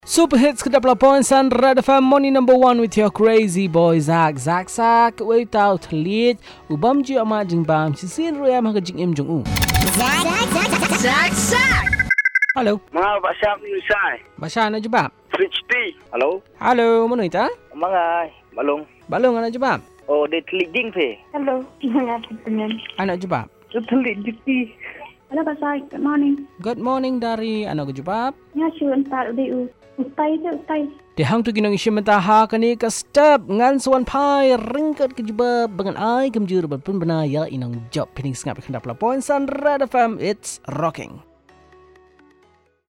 Calls